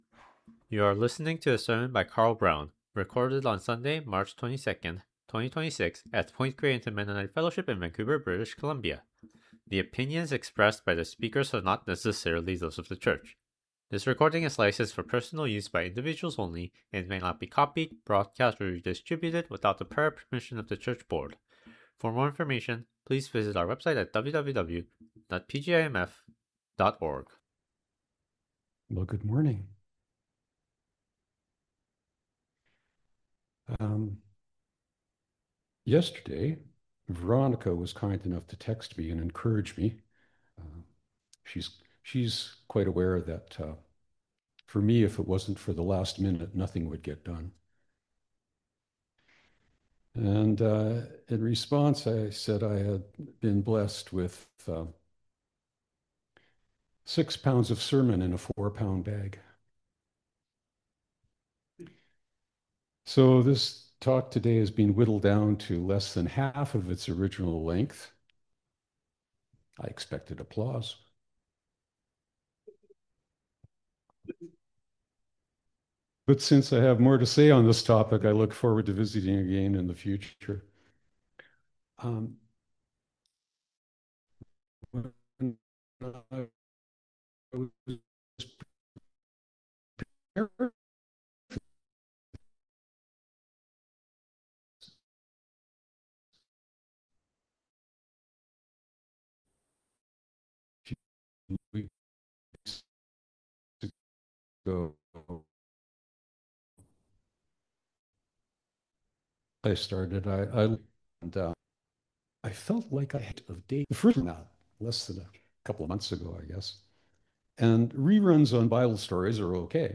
Sermon Recording: Download